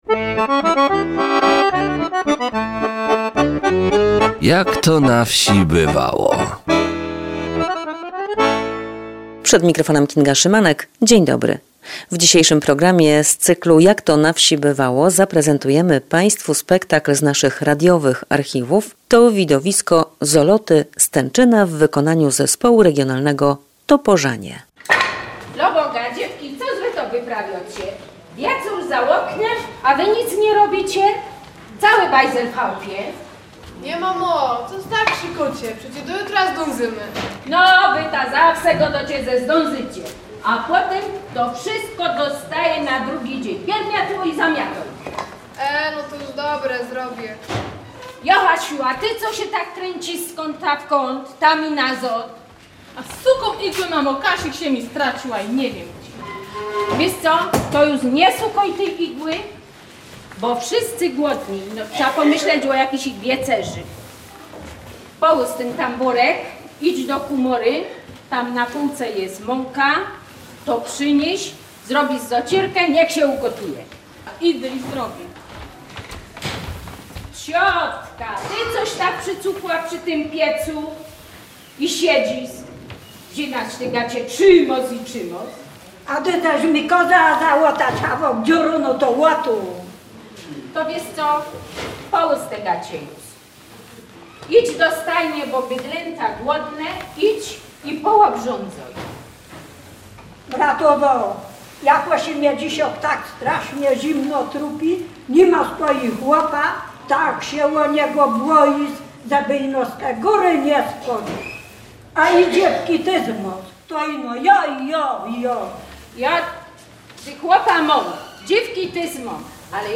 Zoloty z Tenczyna przedstawi Zespół Regionalny Toporzanie z gminy Lubień w województwie małopolskim w Beskidzie Wyspowym.